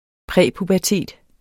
Udtale [ ˈpʁεˀ- ]